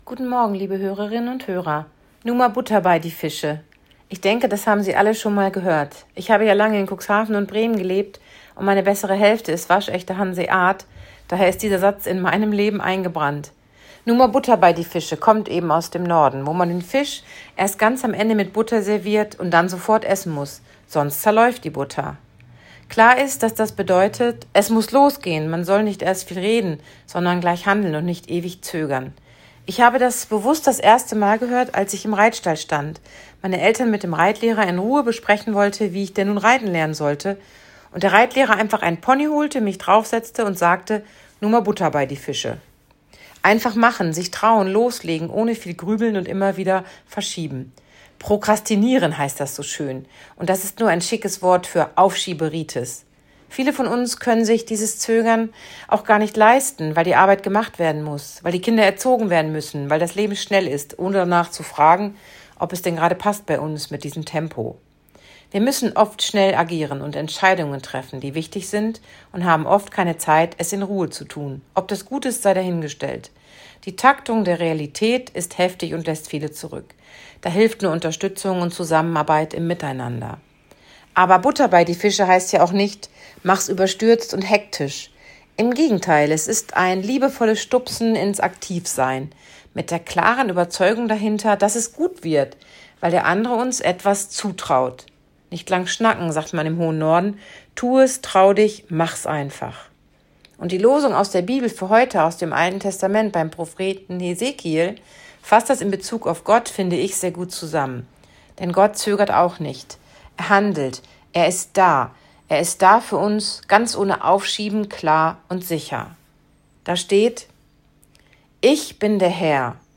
Radioandacht vom 11. September